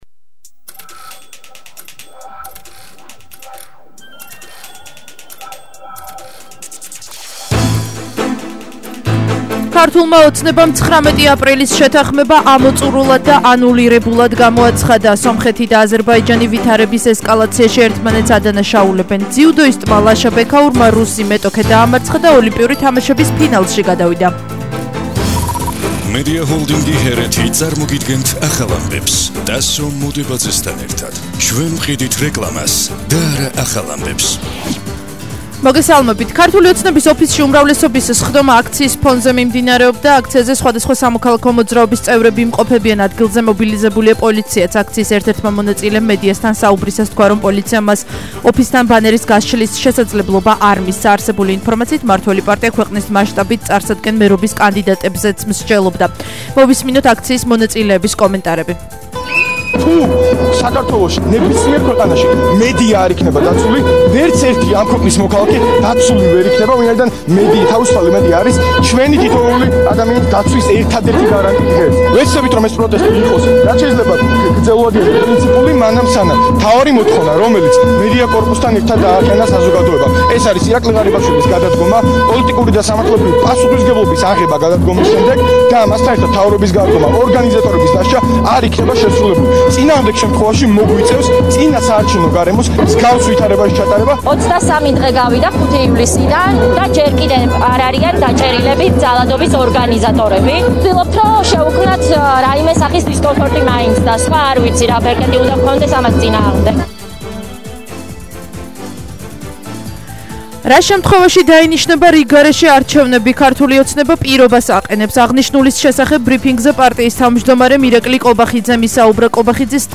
ახალი ამბები 15:00 საათზე –28/07/21 - HeretiFM